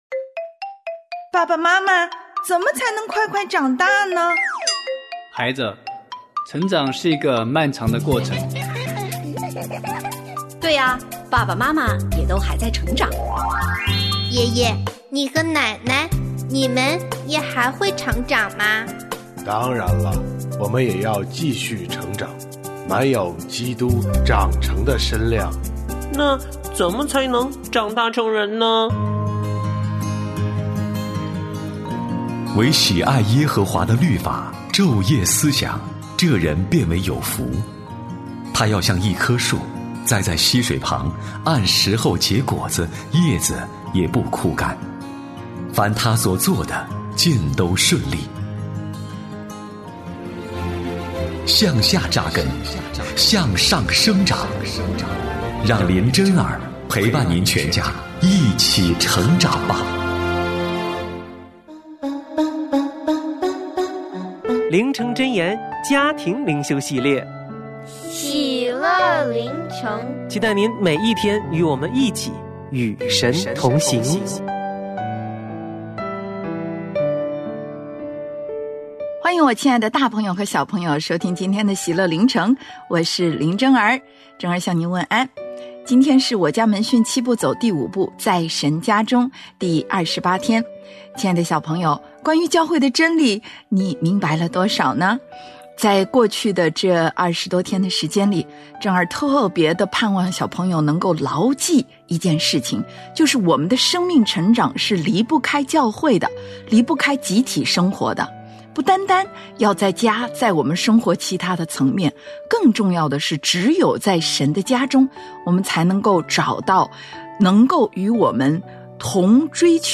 我家剧场：圣经广播剧（116）所罗门王送城回报希兰；示巴女王来访所罗门王